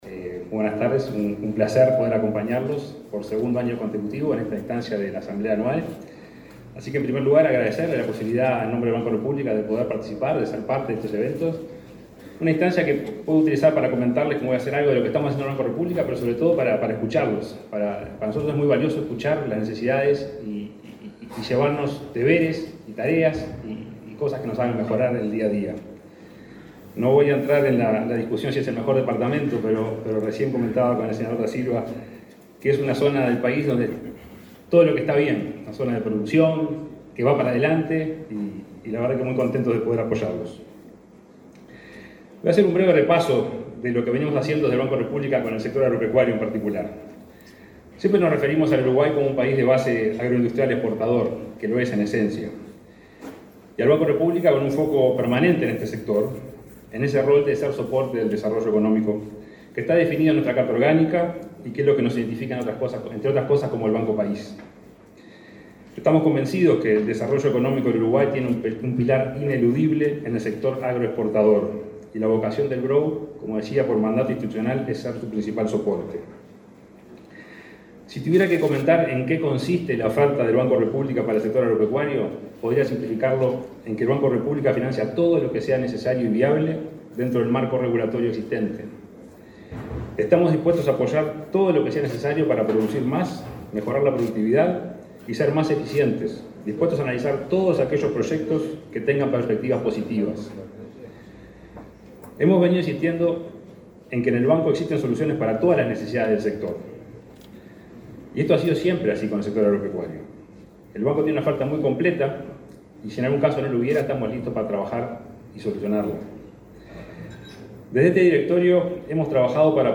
El presidente del Banco República, Salvador Ferrer, participó de la reunión del consejo directivo de fin de año de las Cooperativas Agrarias Federadas